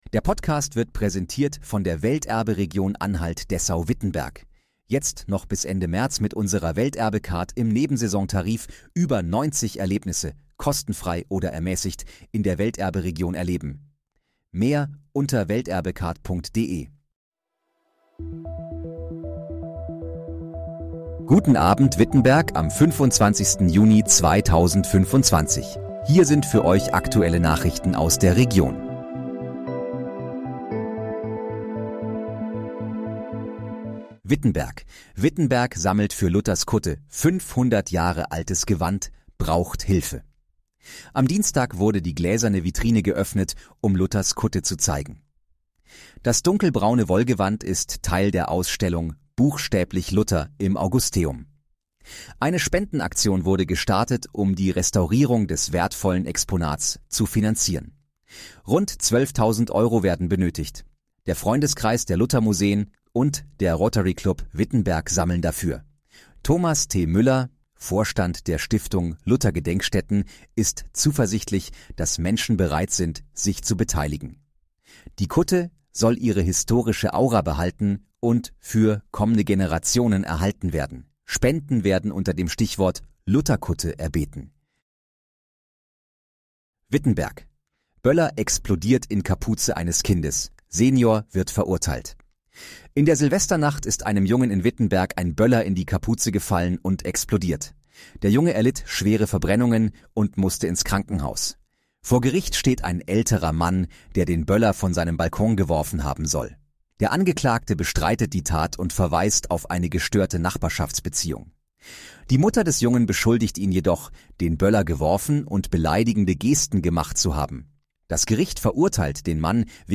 Guten Abend, Wittenberg: Aktuelle Nachrichten vom 25.06.2025, erstellt mit KI-Unterstützung
Nachrichten